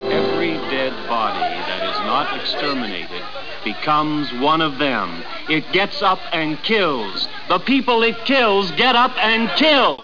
(.wav, 216ko) : Quant à celui-ci, c'est le reportage d'intro de Zombie.